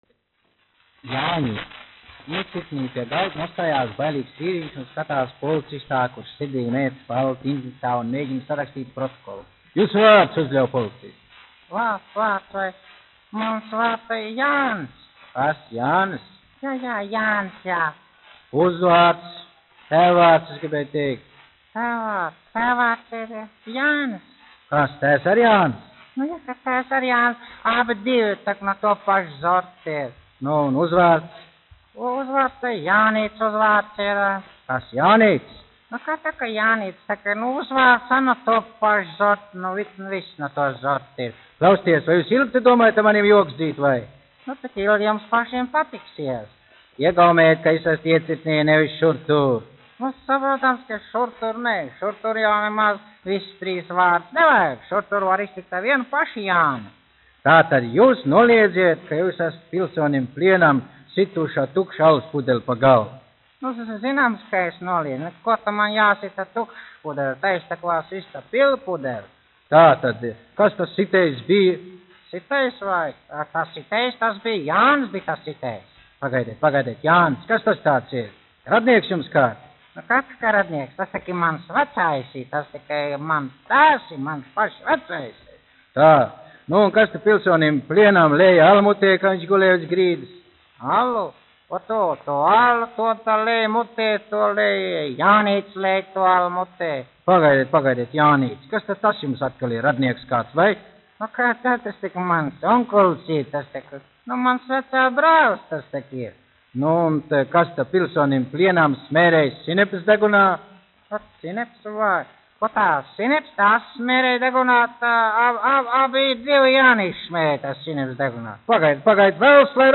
1 skpl. : analogs, 78 apgr/min, mono ; 25 cm
Skaņuplate